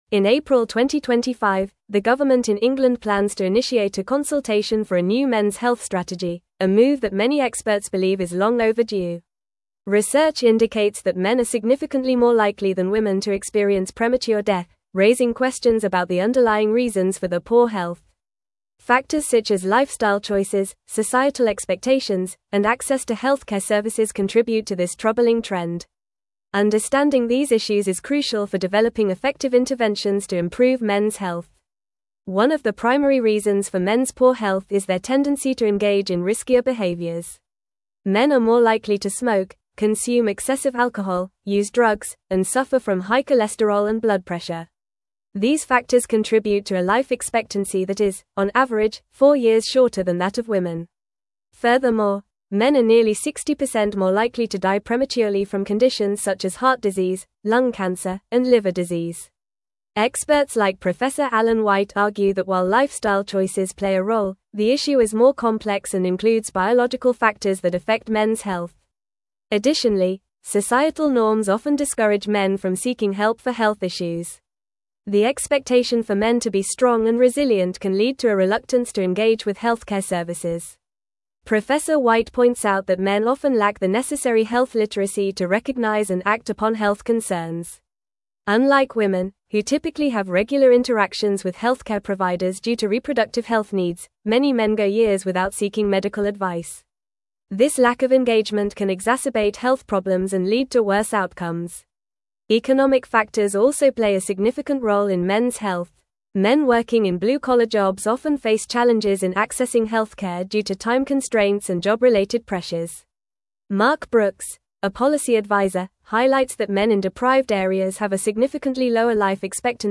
Fast
English-Newsroom-Advanced-FAST-Reading-UK-Government-Launches-Consultation-for-Mens-Health-Strategy.mp3